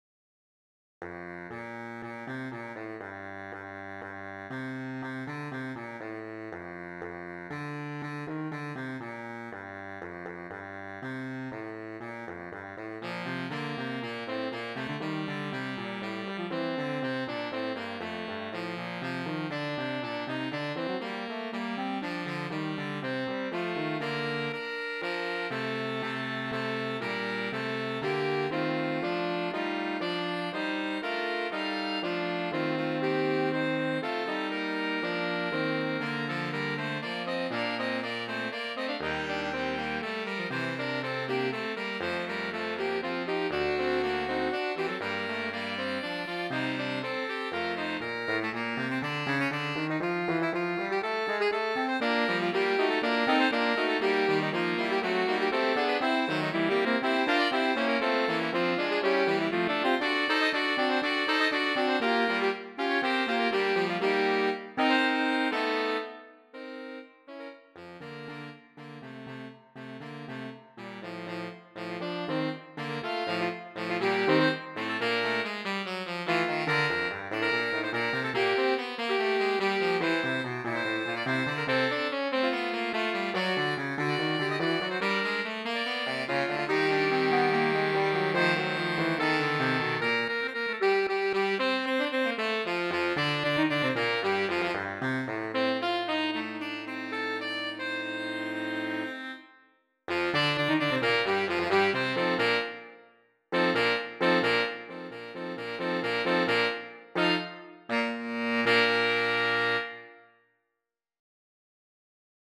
Voicing: 4 Sax